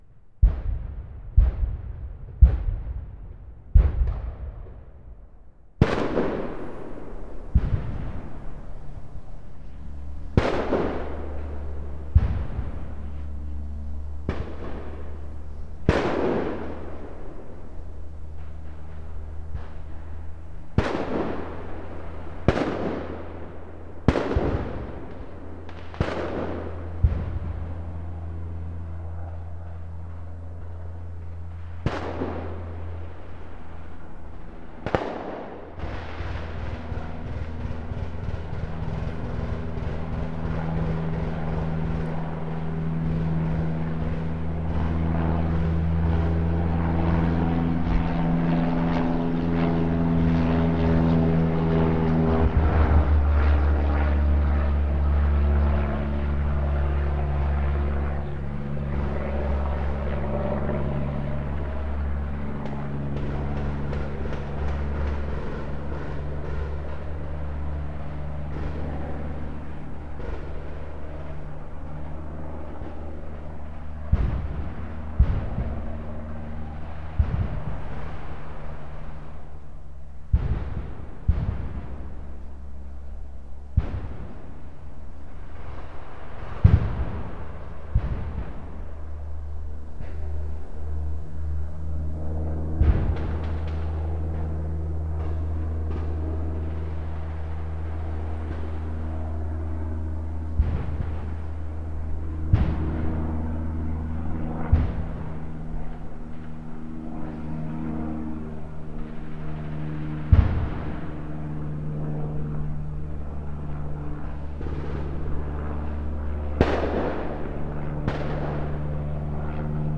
war
Perfect for battle, explosion, fight.
battle explosion fight film gun machine-gun movie scream sound effect free sound royalty free Movies & TV